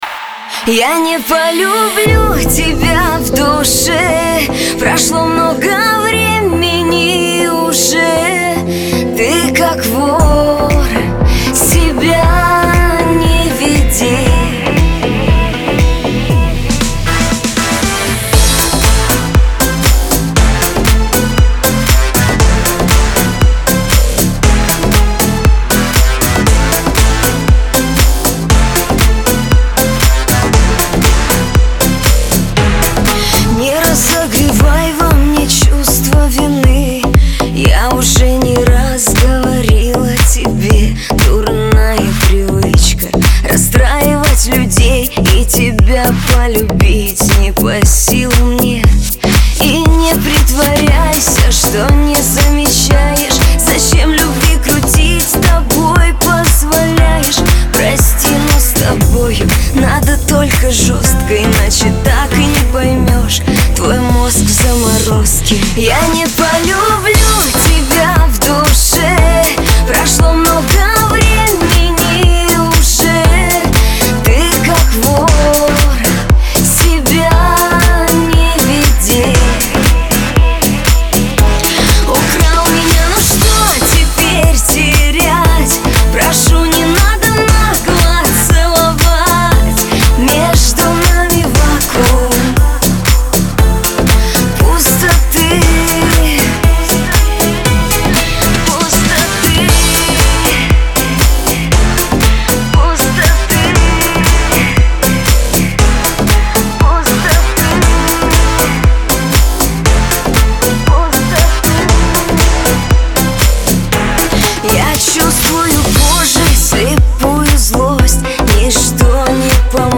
эмоциональный поп-балладный трек с меланхоличным настроением